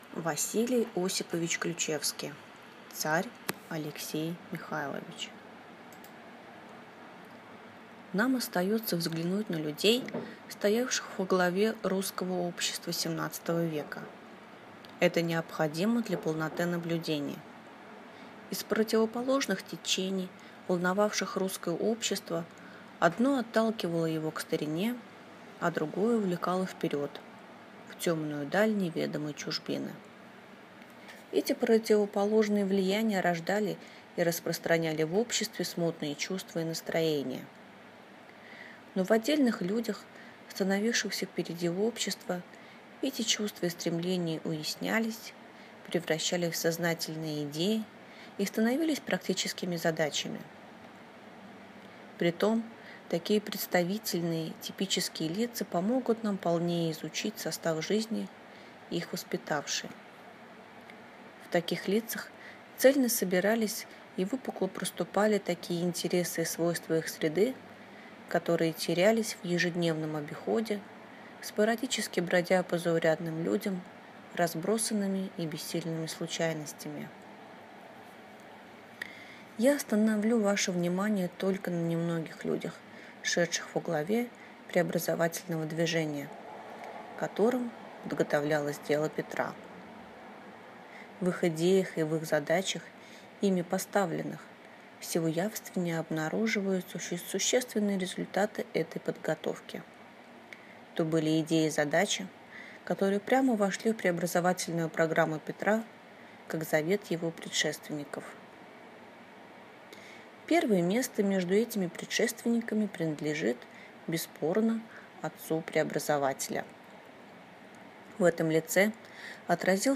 Аудиокнига Царь Алексей Михайлович | Библиотека аудиокниг
Прослушать и бесплатно скачать фрагмент аудиокниги